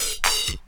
34DR.BREAK.wav